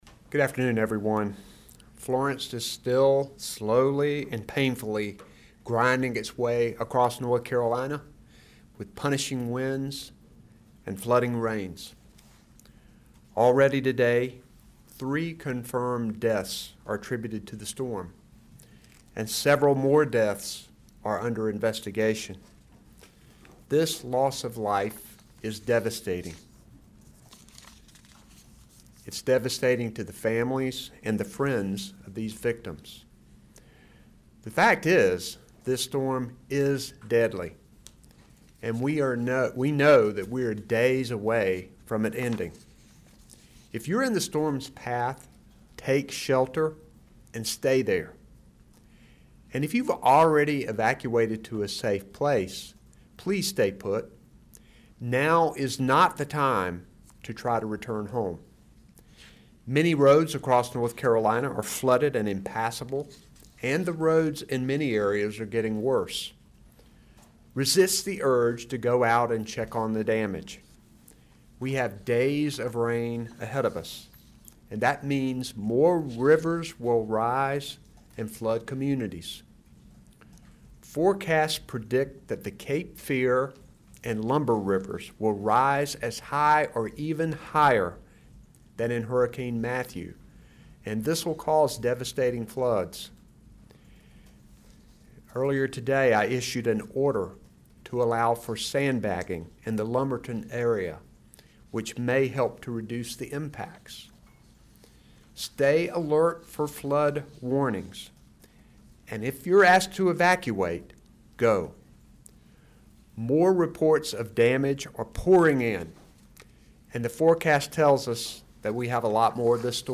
Gov. Roy Cooper and other state officials brief the media as Hurricane Florence lashes North Carolina.
5 pm FULL state briefing.mp3